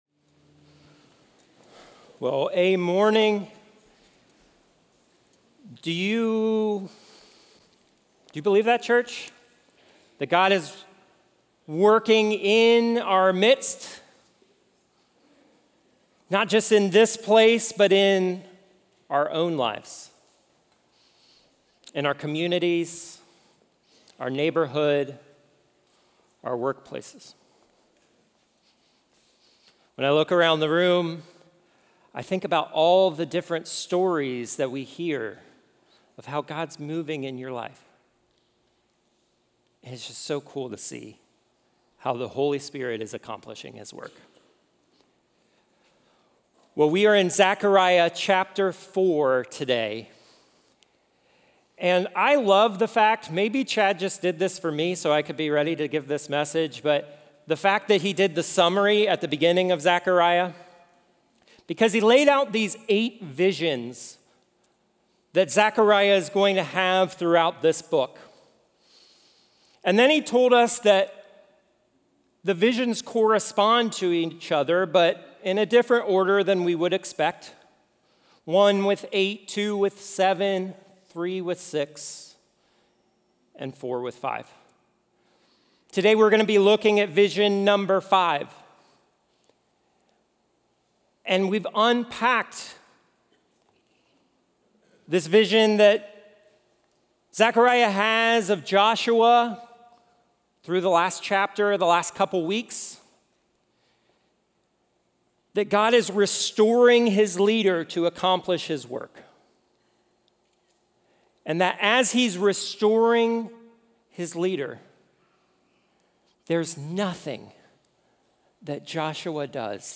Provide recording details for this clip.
Equipping Service / Zechariah: Hope-Ray Vision / How the Holy Spirit Empowers